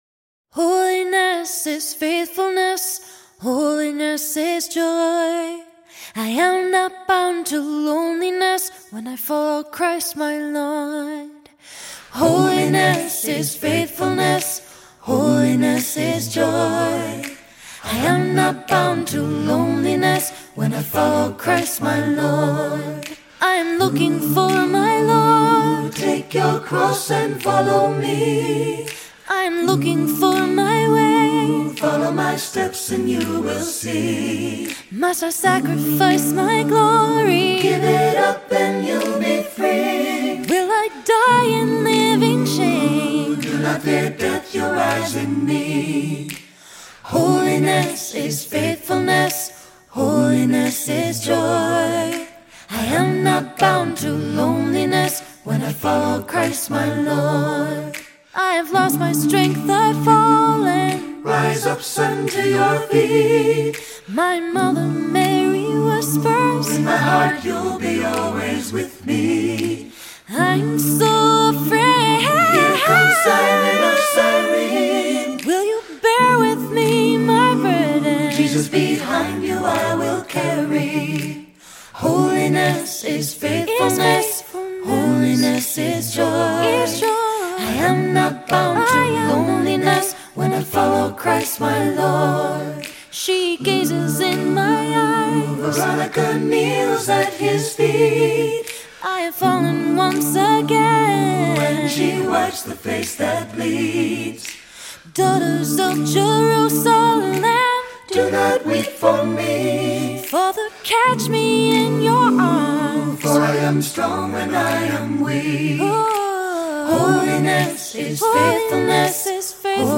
Voicing: Assembly,SATB,Soloist or Soloists